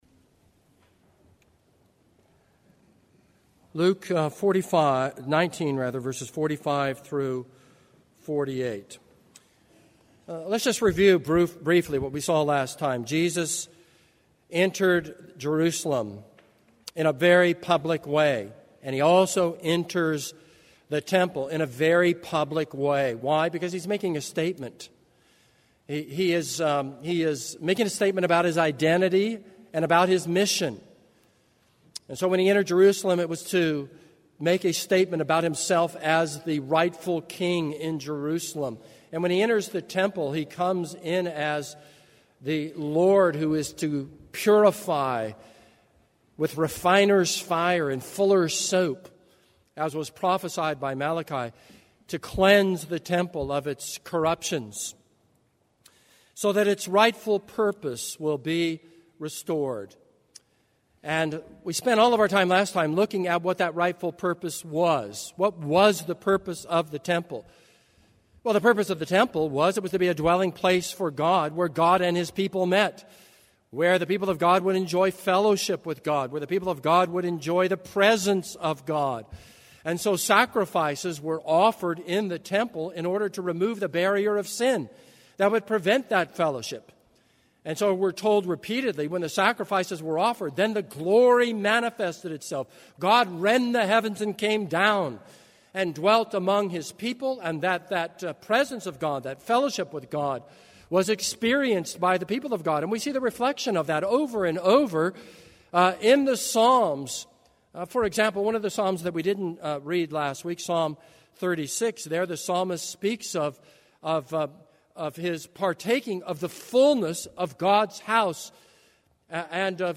This is a sermon on Luke 19:45-48.